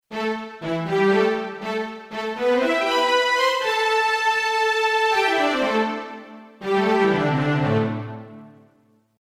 strings
strings.mp3